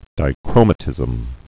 (dī-krōmə-tĭzəm) also di·chro·mism (-mĭzəm)